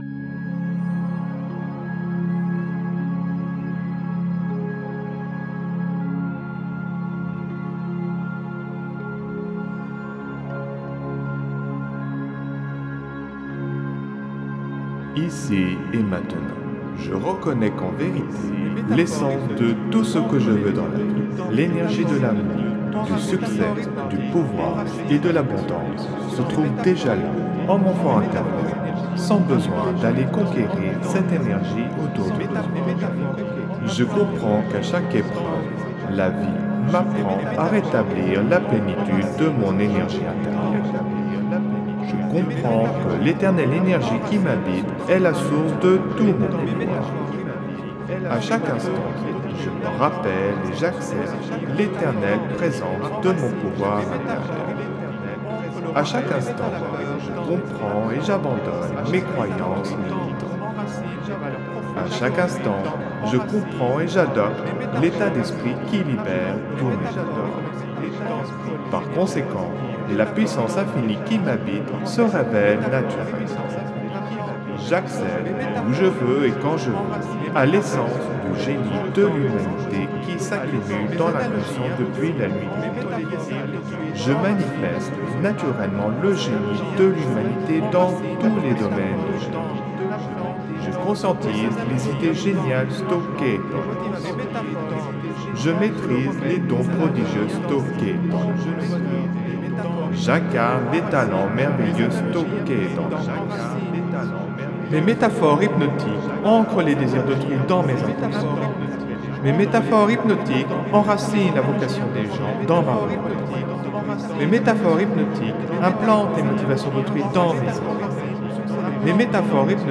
Son binaural, message subliminal, auto hypnose, méditation, PNL, musicothérapie
SAMPLE-Suggestion-hypnotique-echo.mp3